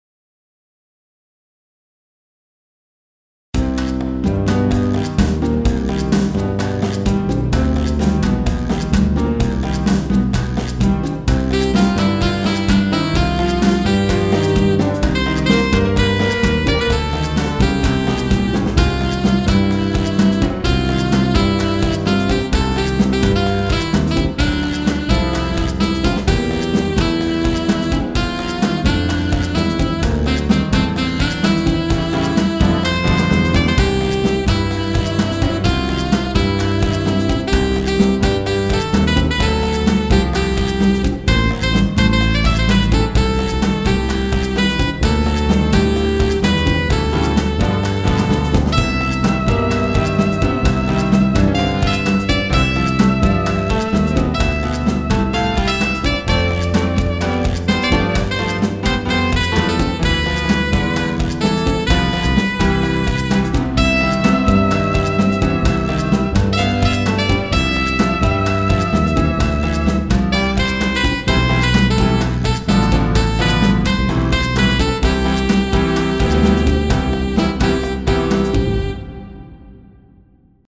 BGM
ショートジャズ